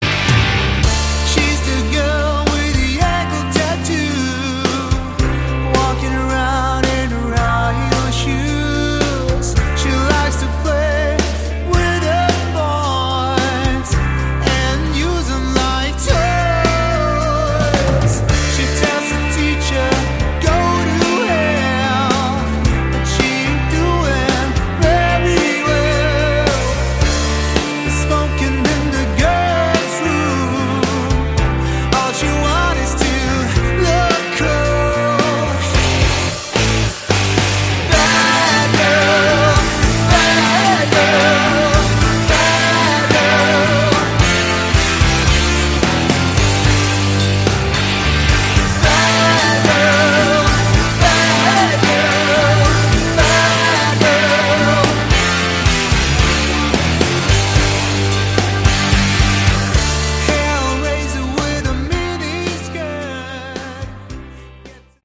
Category: Hard Rock
vocals, guitar
bass, backing vocals
drums, percussion